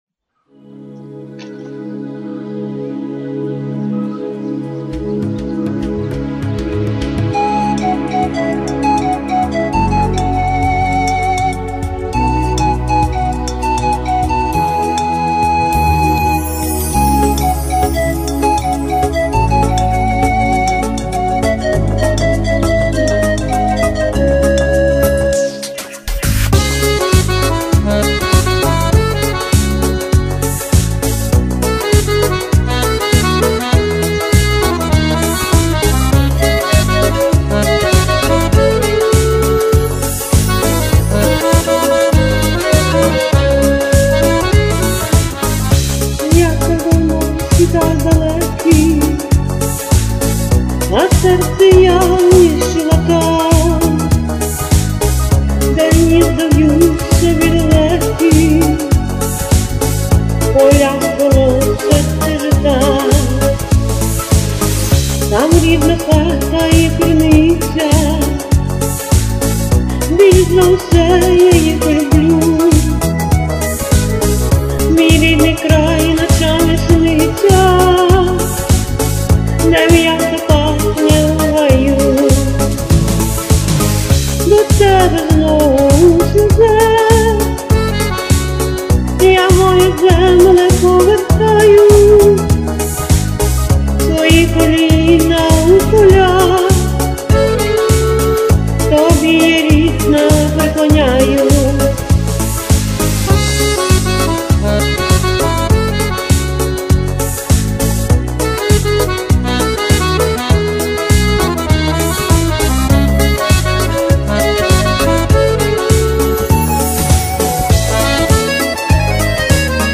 Подайте текст, бо слова невиразно чути.